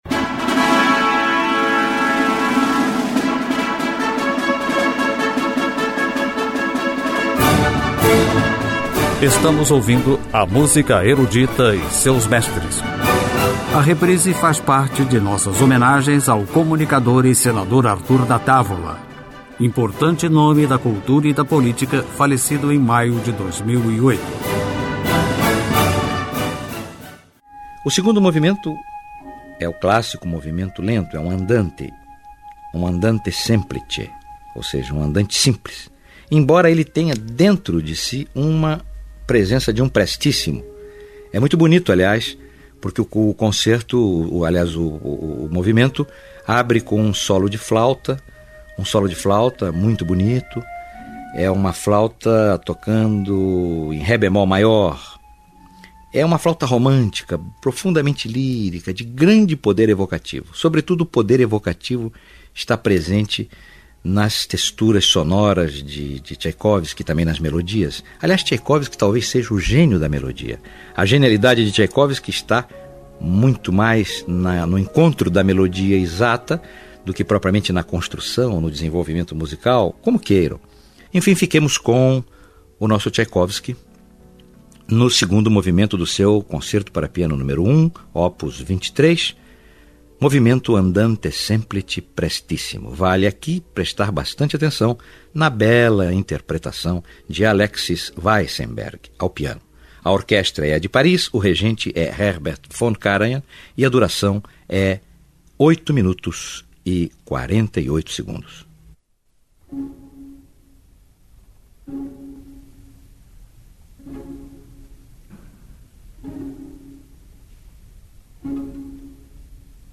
Período clássico Impressionismo Música polifônica e tonal